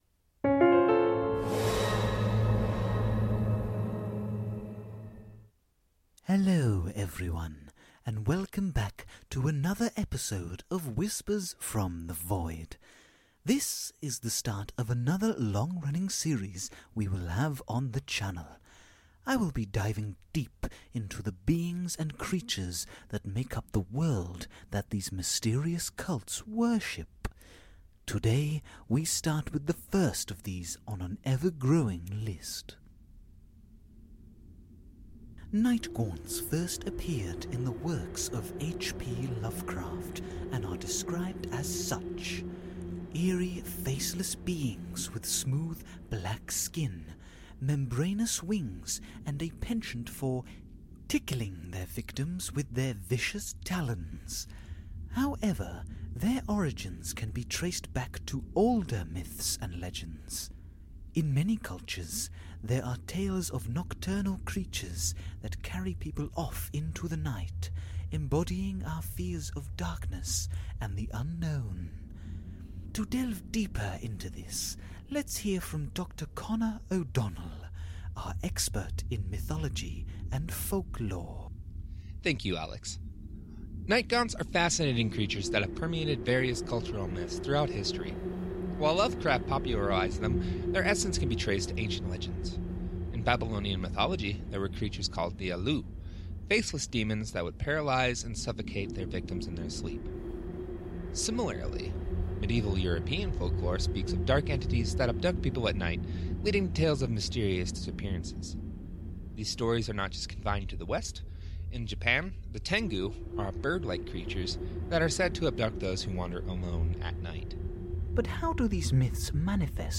Play Rate Apps Listened List Bookmark Share Get this podcast via API From The Podcast Whispers From the Void Whispers from the Void is an immersive audio drama that plunges listeners into a world where the boundaries between reality and the supernatural are blurred.